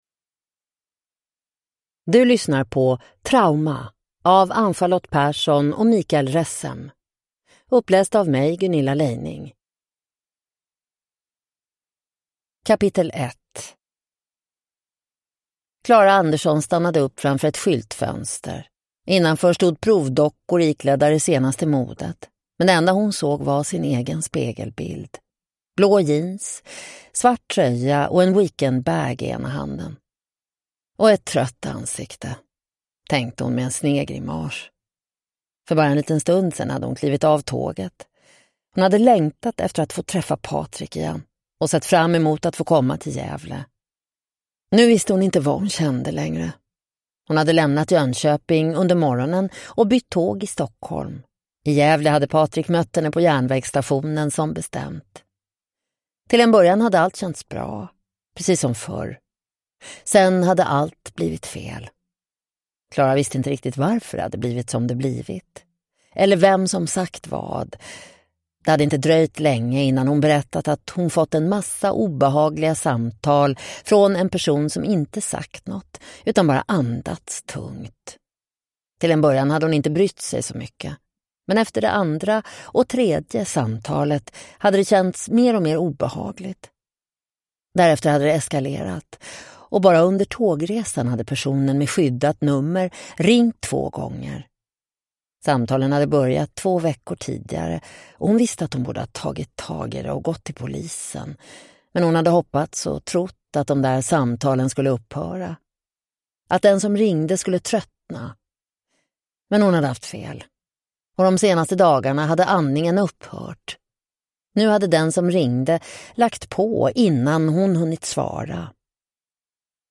Trauma (ljudbok) av Mikael Ressem